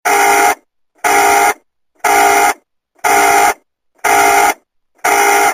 Kategori Alarm